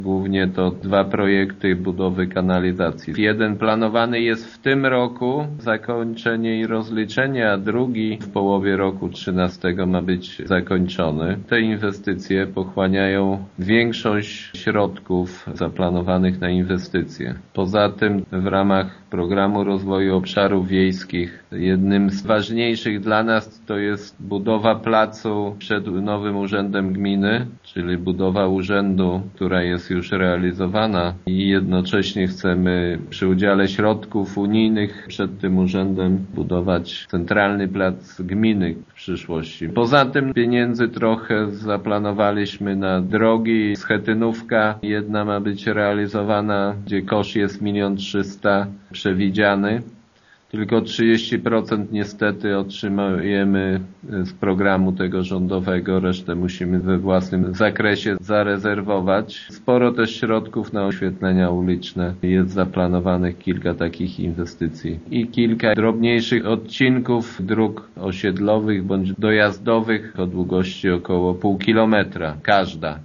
Wśród inwestycji najwięcej pieniędzy samorząd zamierza wydać na realizację wieloletnich zadań, które są współfinansowane ze źródeł zewnętrznych. – Chodzi przede wszystkim o inwestycje w sieć kanalizacyjną i drogi – mówi Informacyjnej Agencji Samorządowej wójt Jacek Anasiewicz: